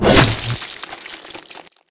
pslash.ogg